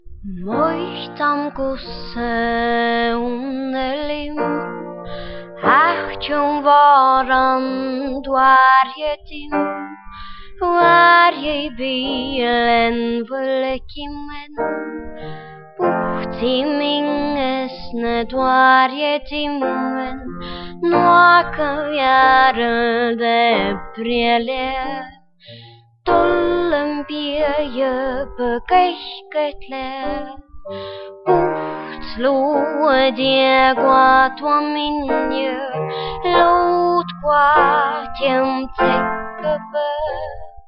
Here’s a recording of part of a song in a mystery language.